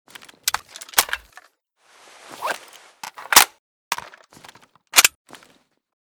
ppsh_reload_empty.ogg.bak